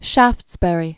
(shăftsbĕrē, -bə-rē), First Earl of.